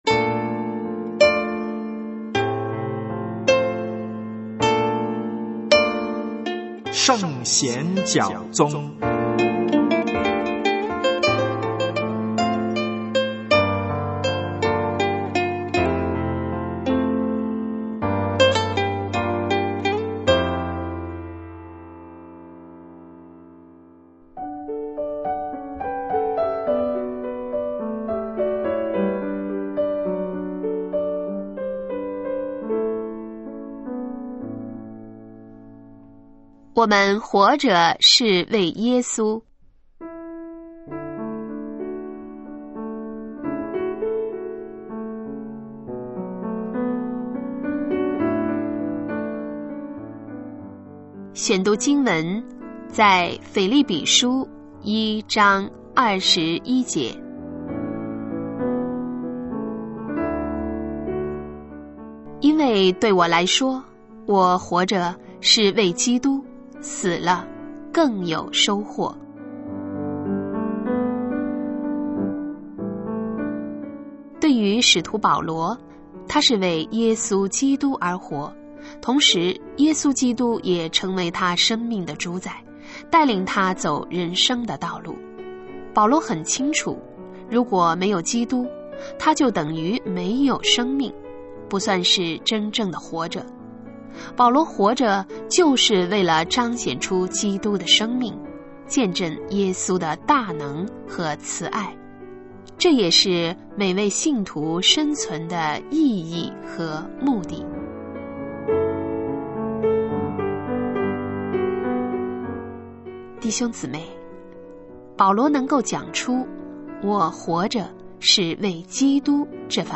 真道分解《圣贤脚踪：我们活着是为耶稣》2014年12月31日